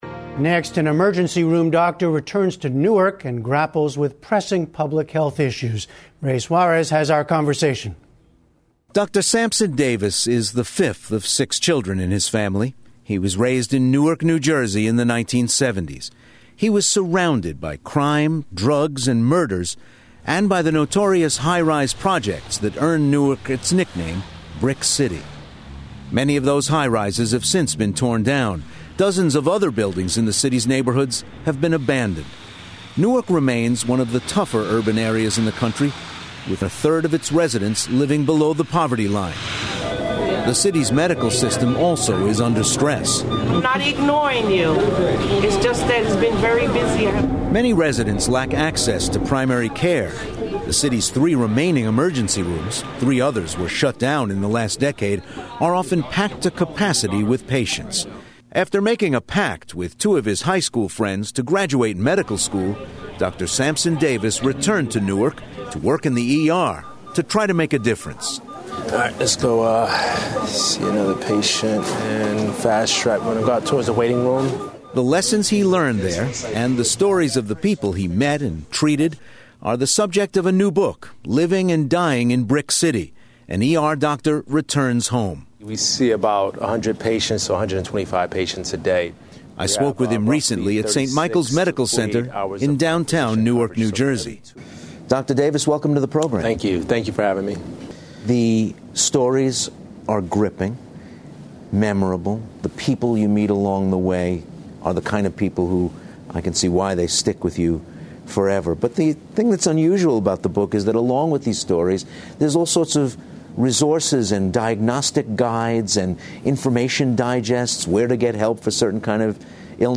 英语访谈节目:急诊室医生"砖城"寻根之旅